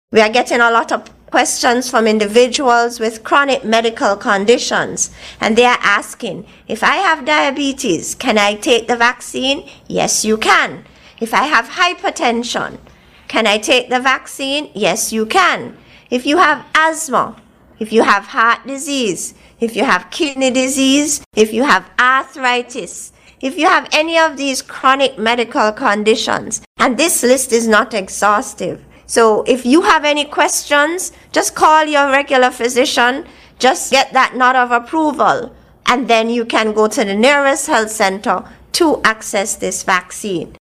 Chief Medical Officer, Dr. Hazel Laws, addressed this question during Wednesday’s NEOC COVID-19 briefing: